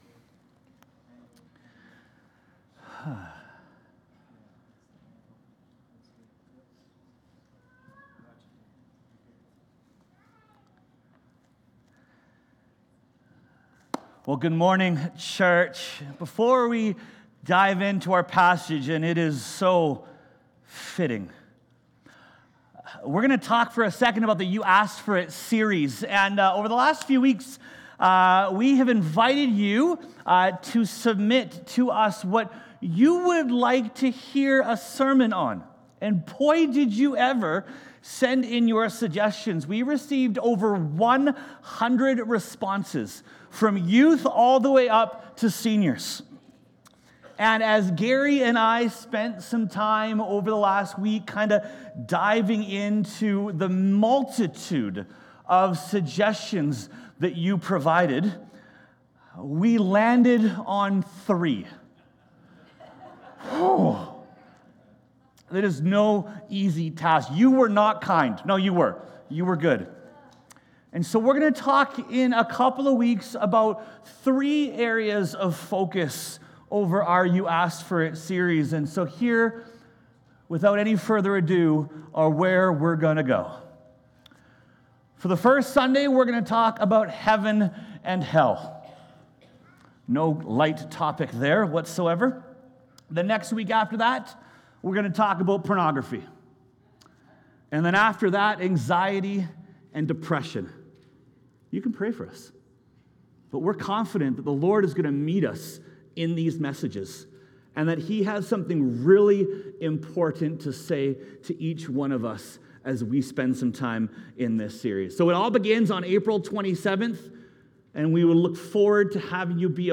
Sermons | Westview Baptist Church